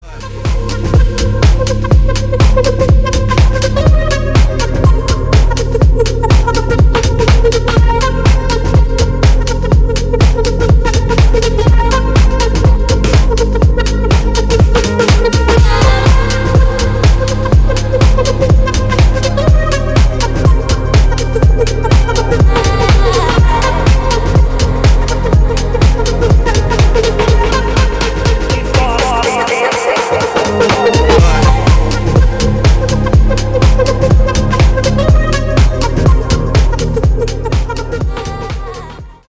• Качество: 320, Stereo
deep house
EDM
Melodic house
Клубная танцевальная музыка